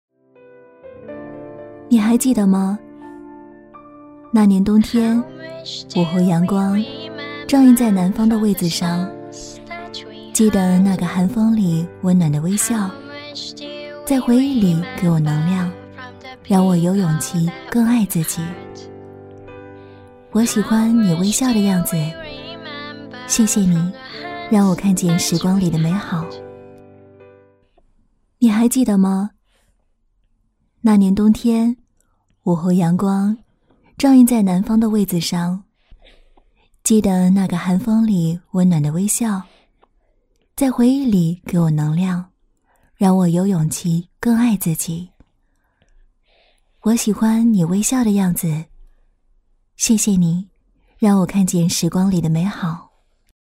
女158-微电影-猫的树（青春甜美）
女158-中英双语 质感磁性
女158-微电影-猫的树（青春甜美）.mp3